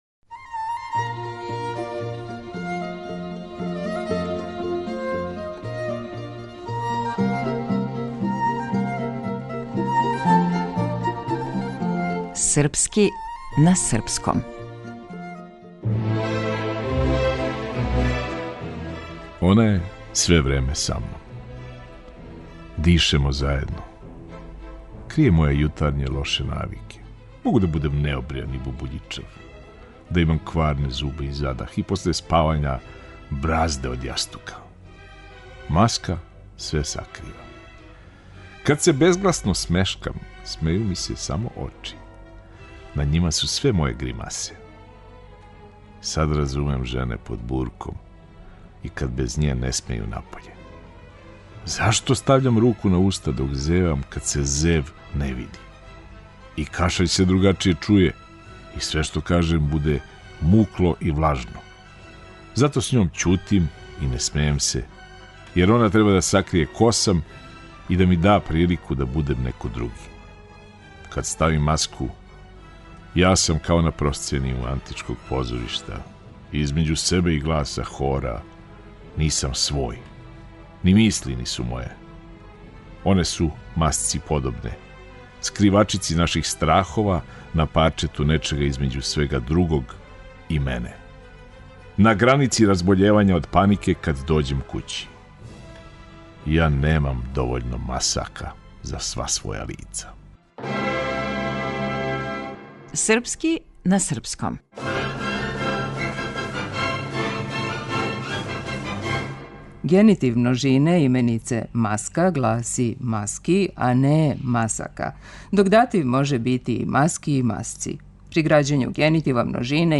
Глумац - Никола Којо